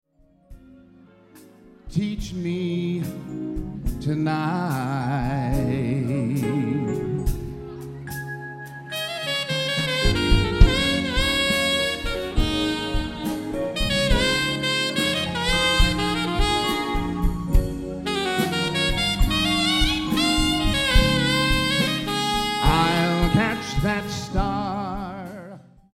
on alto